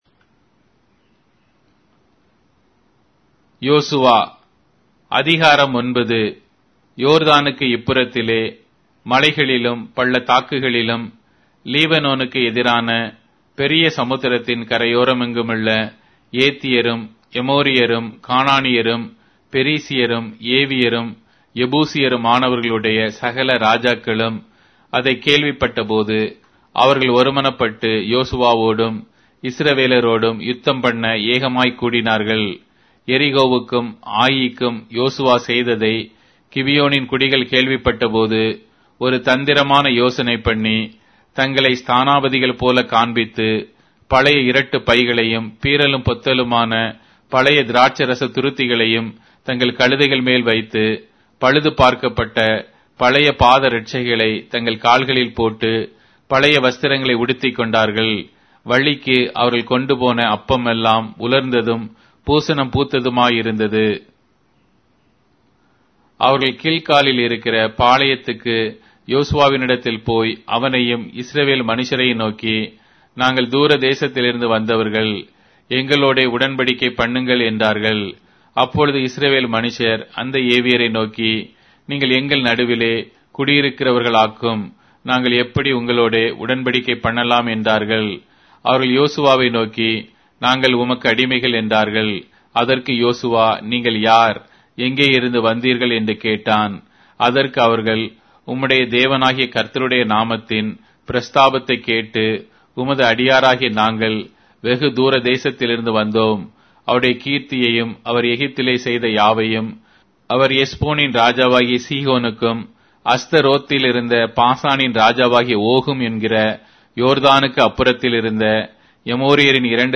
Tamil Audio Bible - Joshua 5 in Urv bible version